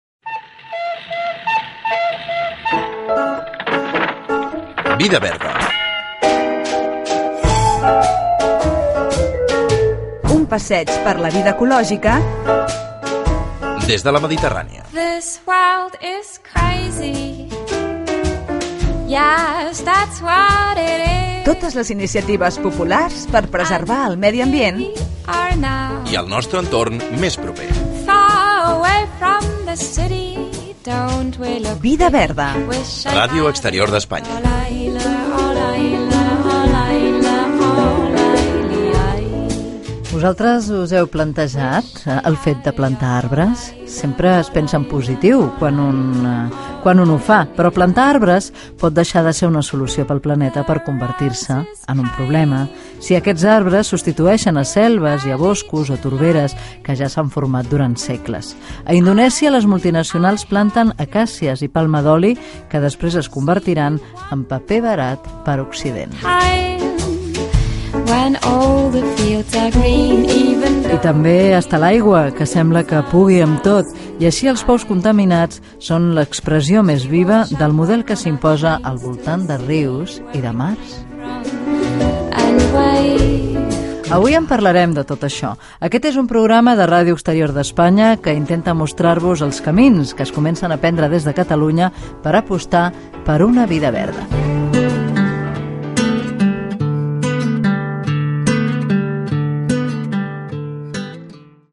Careta del programa, presentació amb la identificació de l'emissora
Divulgació